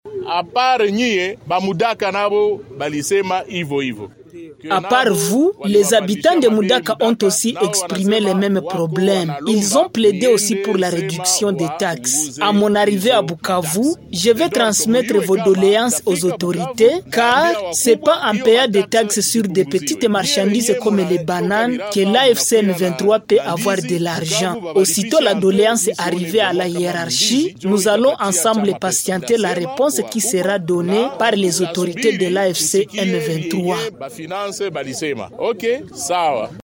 En réponse à ces plaintes, le Gouverneur de province rassure avoir pris en compte toutes ces plaintes et a promis son implication personnelle pour une réponse favorable car, a-t-il indiqué, l’AFC/M23 est venu pour alléger les souffrances de la population.
Signalons qu’au cours de cette tournée dans le territoire de Kabare, le Gouverneur Emmanuel Birato était accompagné de certains membres de son cabinet et d’autres cadres de l’AFC/M23.